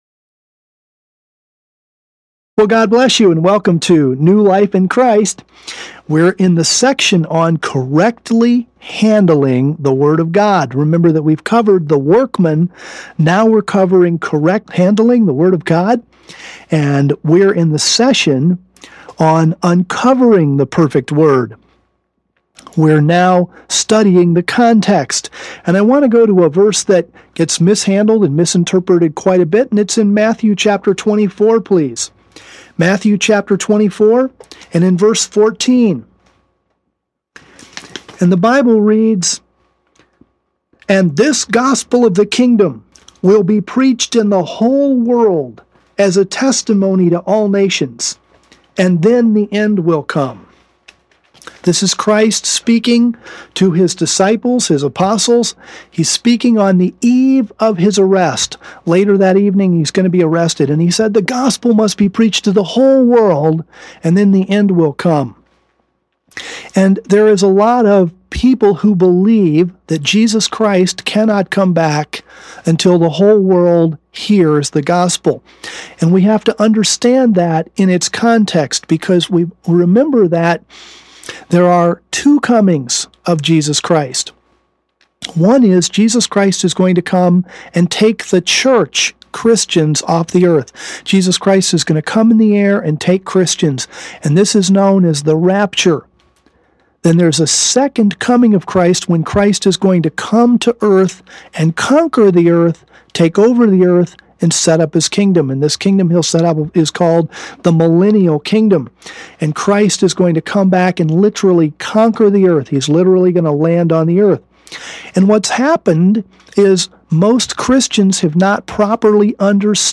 These are context, scope, customs, figures of speech, and errors in translation or transmission. The teachers illustrate each of these keys through the scriptures in a most intriguing fashion.